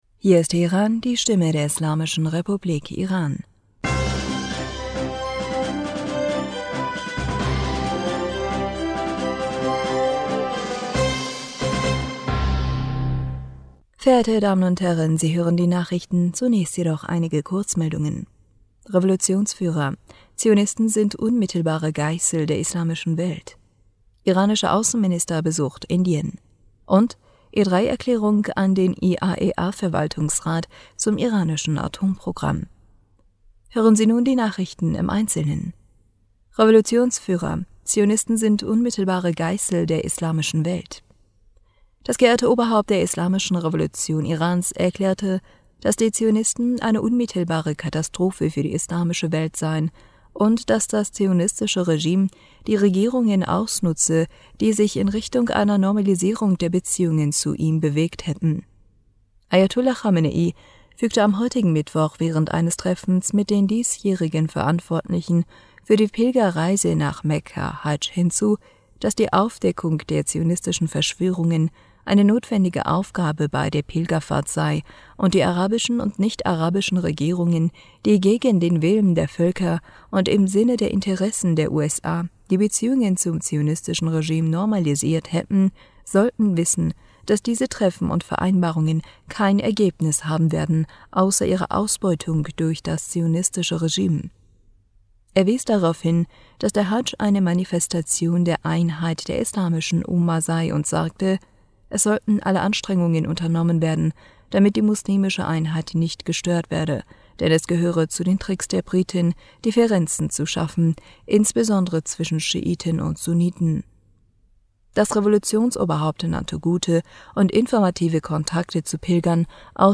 Die Nachrichten vom Mittwoch dem 8. Juni 2022